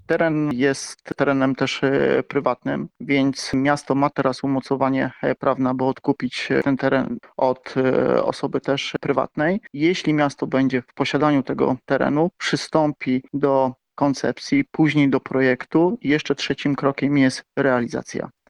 O dalsze działania związane z realizacją projektu zapytaliśmy stargardzkiego radnego Grzegorza Rybaczuka.